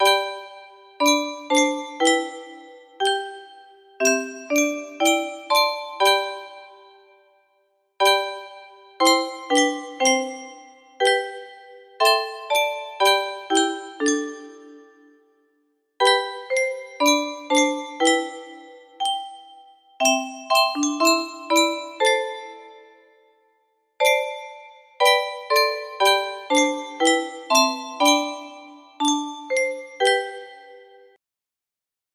Henry Francis Lyte - Abide with me 2 - 31 Bars music box melody